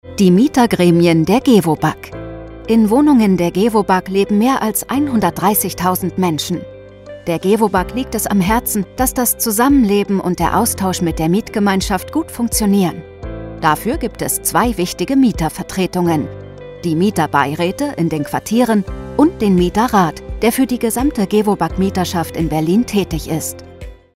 Sprecherin für Werbung, Imagefilme, Hörbücher, Dokumentationen und Co.
Sprechprobe: eLearning (Muttersprache):